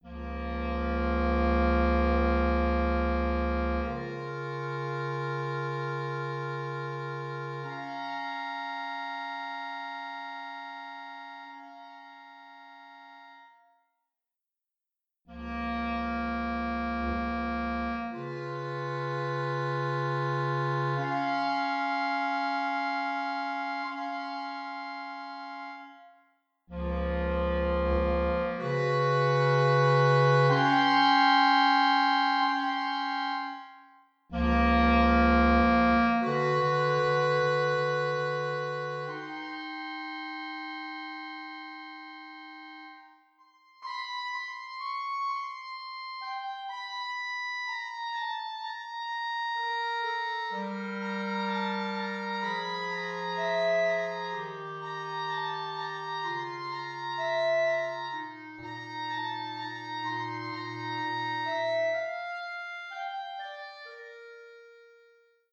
3 Bassetthörner